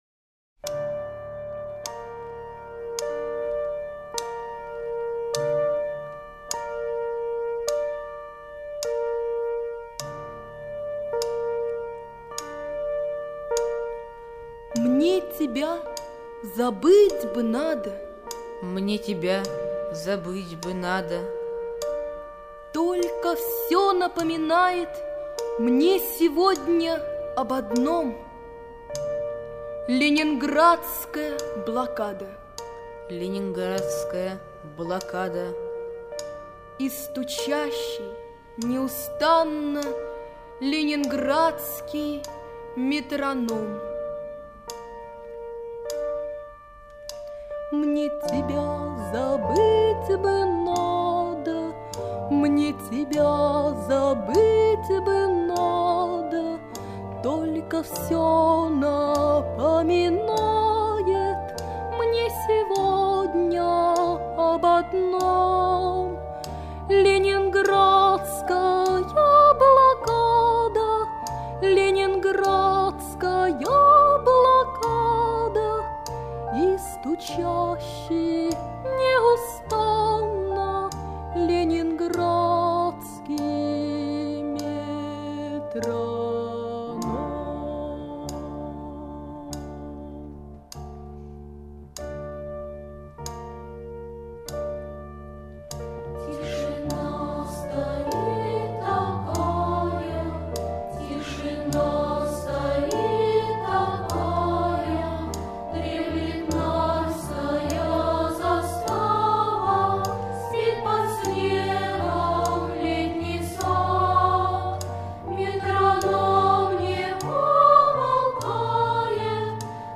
В блокадном Ленинграде, когда радио не работало, в эфире стучал метроном: быстрый ритм означал воздушную тревогу, медленный ритм — отбой.
По окончании передач звучал метроном - его стук называли живым биением сердца Ленинграда.
leningradskiy-metronom.mp3